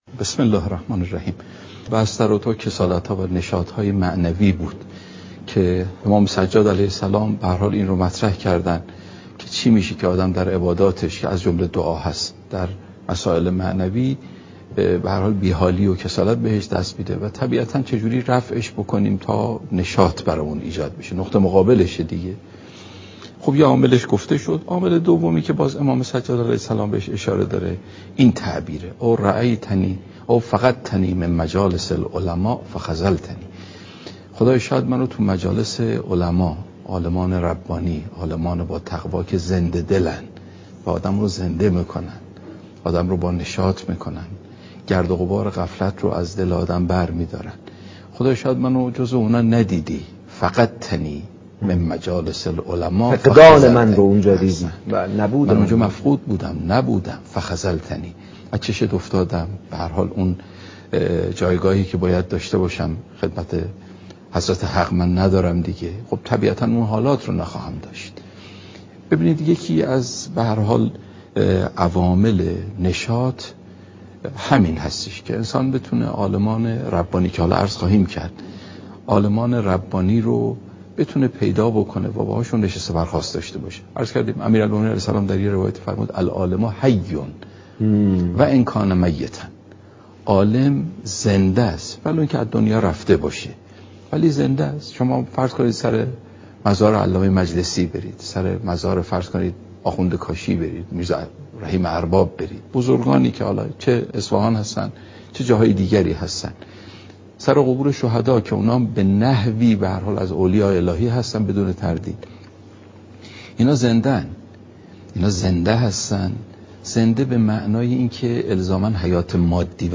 سخنرانی ماه رمضان